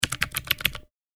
キーボード | 無料 BGM・効果音のフリー音源素材 | Springin’ Sound Stock
タイピング-メカニカル短2.mp3